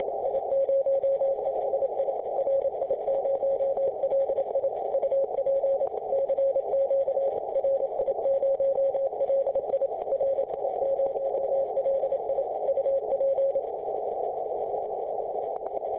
RI0LI, AS-022 30CW